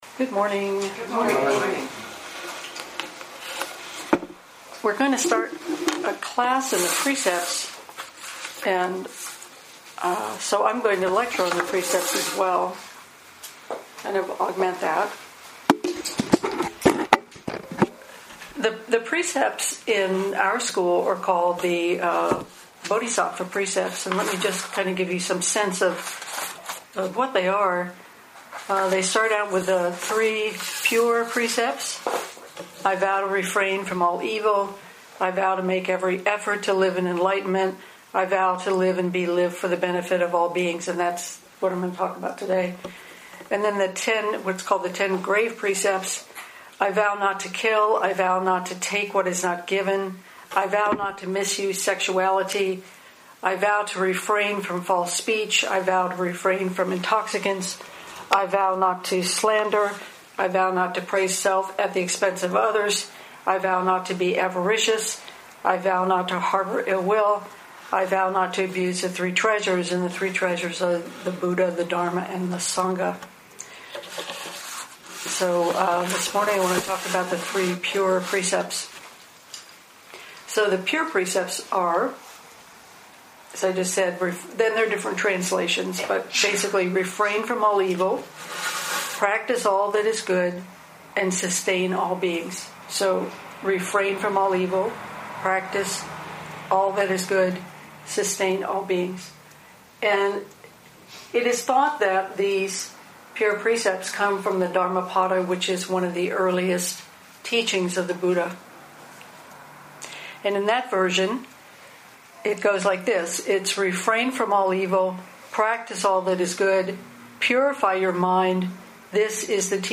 3-Pure-precepts-public-lecture-edited.mp3